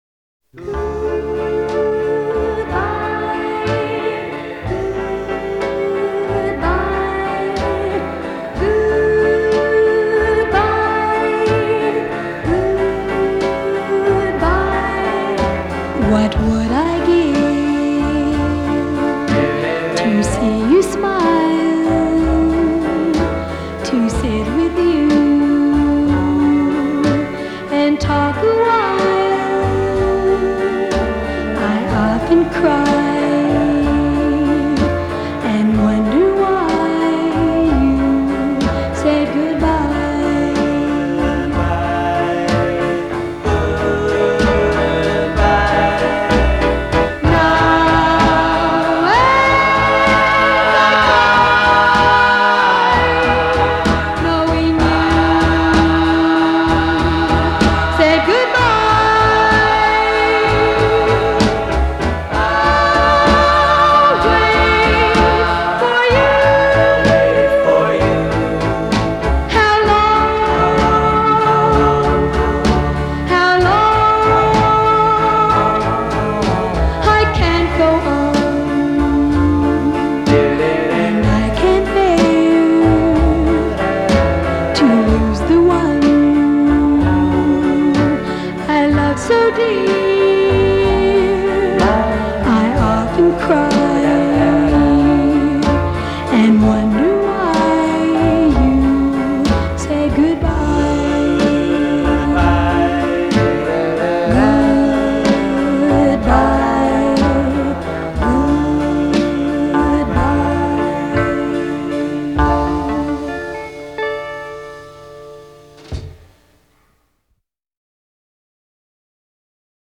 Жанр: Pop, Oldies, Doo-Woop
lead vocals